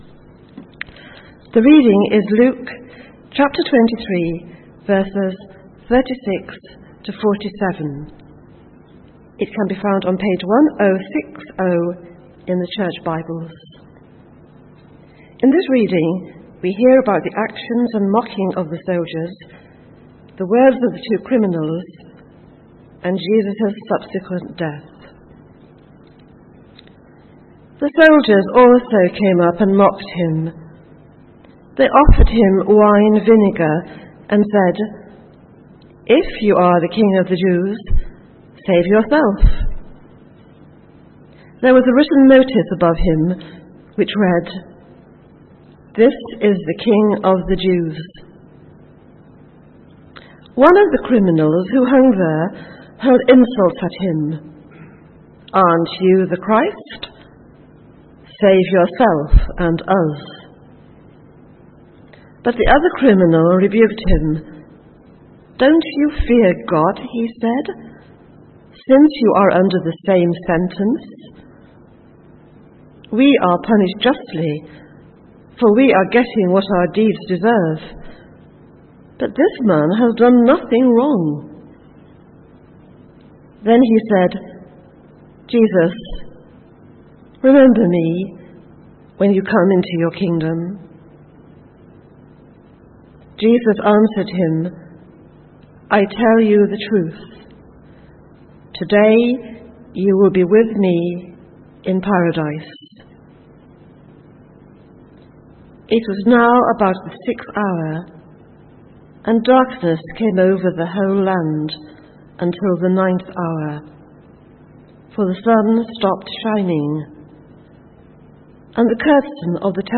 From Service: "9.00am Service"